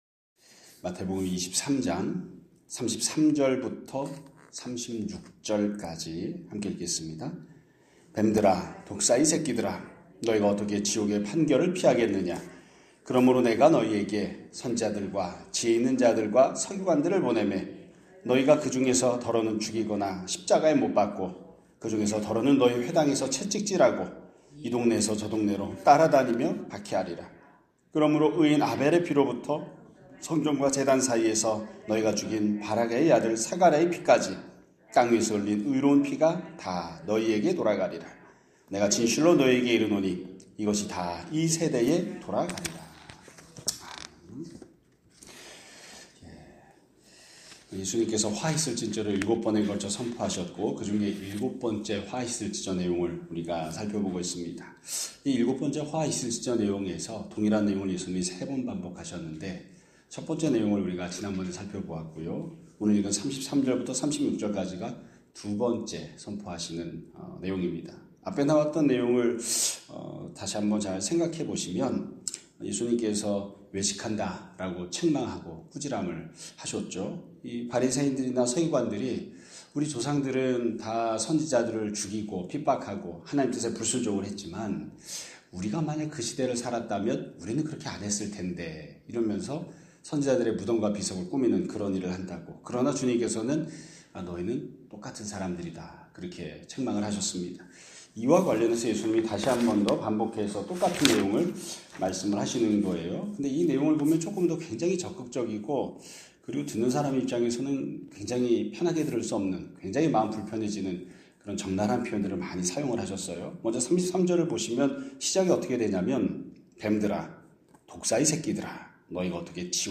2026년 3월 3일 (화요일) <아침예배> 설교입니다.